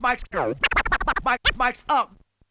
SCRATCHING  (6).wav